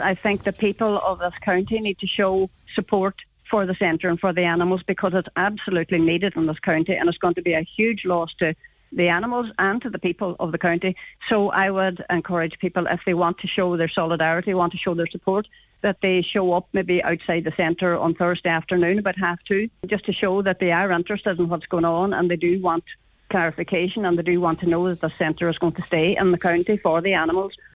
On today’s Nine til Noon Show, she urged people to show their support later this week……..